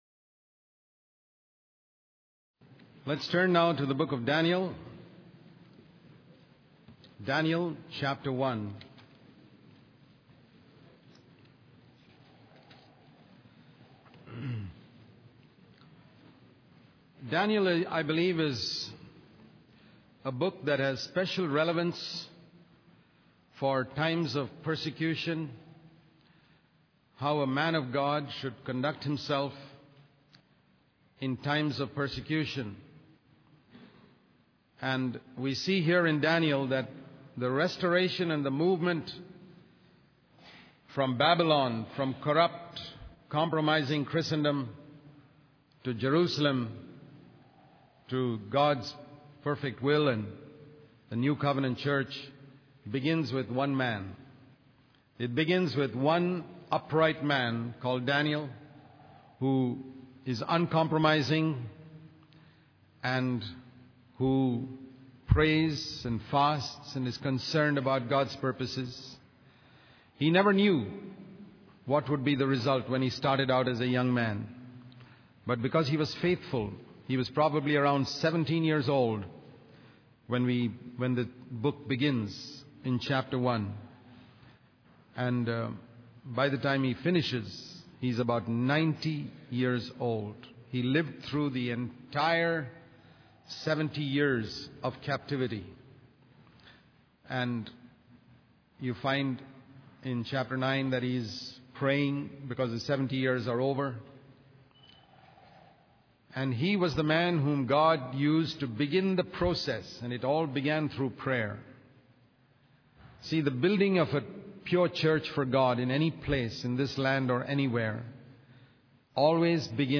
In this sermon, the preacher emphasizes that the influence of a few faithful individuals can have a significant impact on a village or country, rather than relying on numbers.